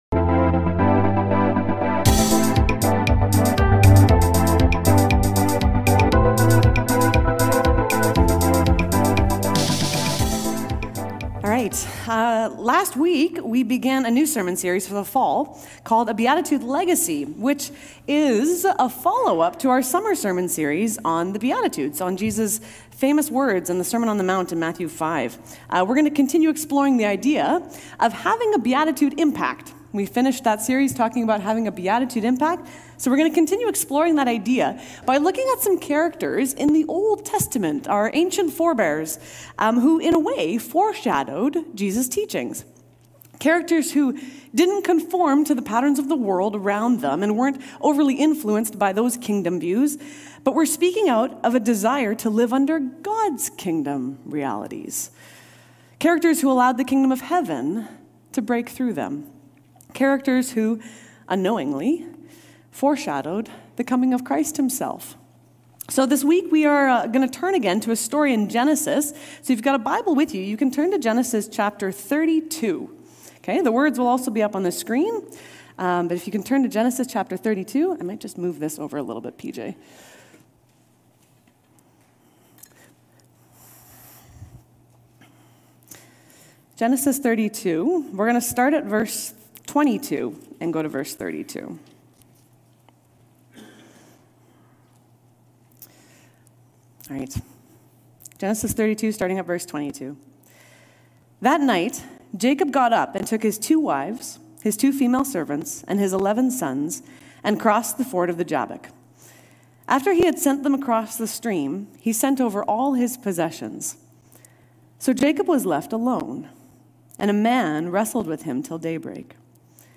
Fall Sermon Series – A Beatitude Legacy: Old Testament Forebears of the Kingdom Come Near: This Fall’s sermon series is a follow-up to the summer Beatitude series.